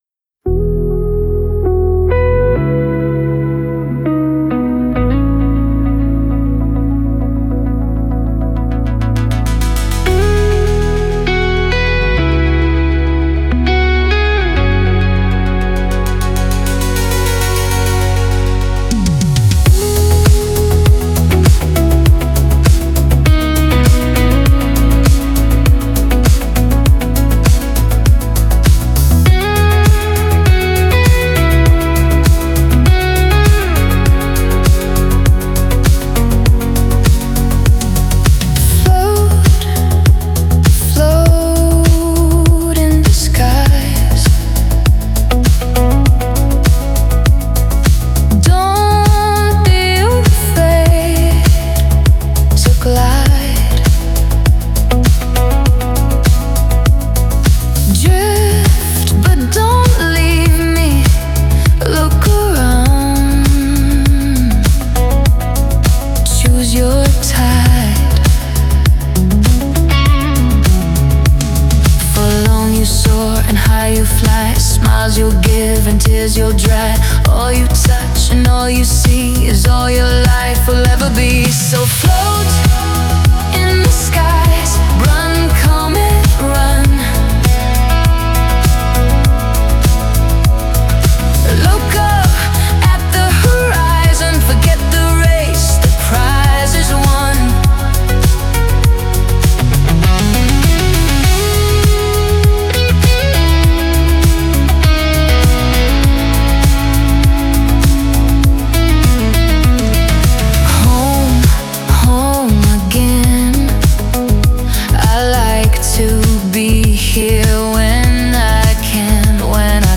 Genre Progressive Rock